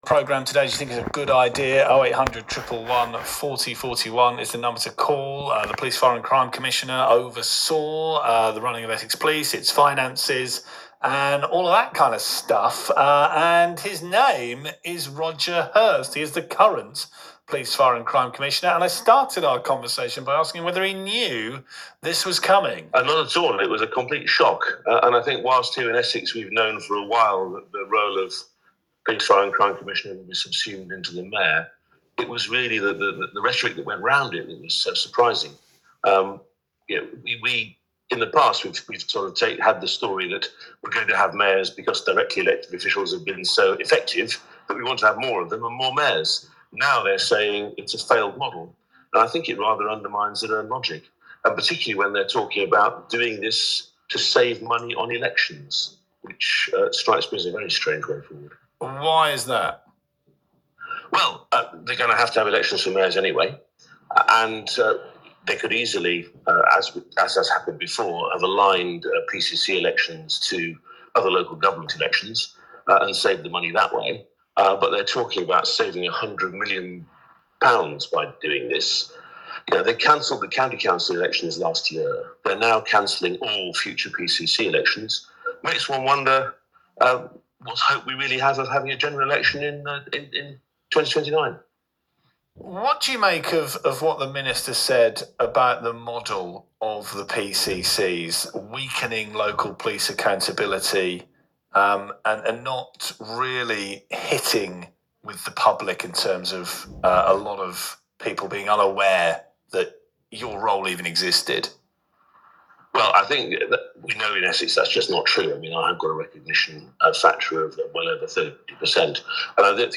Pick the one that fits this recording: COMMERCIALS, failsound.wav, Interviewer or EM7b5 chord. Interviewer